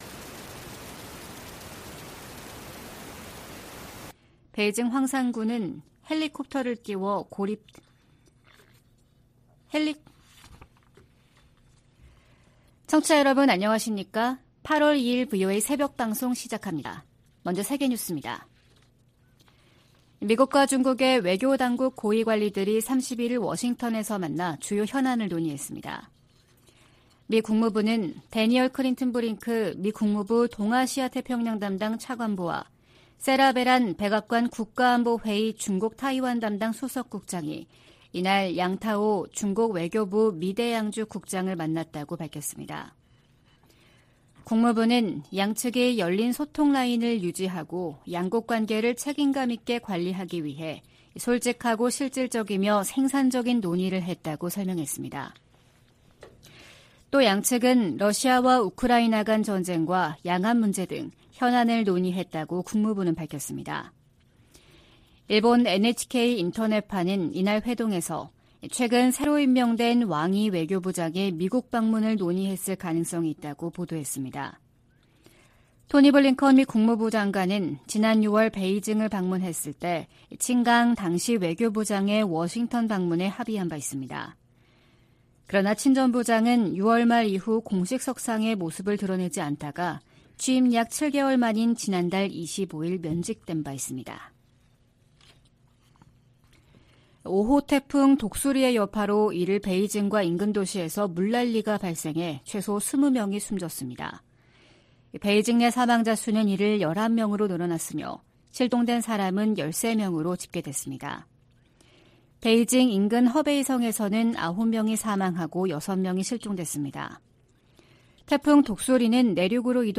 VOA 한국어 '출발 뉴스 쇼', 2023년 8월 2일 방송입니다. 린다 토머스-그린필드 유엔 주재 미국대사가 북한의 식량 불안정 문제는 정권이 자초한 것이라고 지적했습니다. 제11차 핵확산금지조약(NPT) 평가회의 첫 준비 회의에서 주요 당사국들은 북한이 비확산 체제에 도전하고 있다고 비판했습니다. 북한과 러시아 간 무기 거래 가능성이 제기되는 데 대해 미국 국무부는 추가 제재에 주저하지 않을 것이라고 강조했습니다.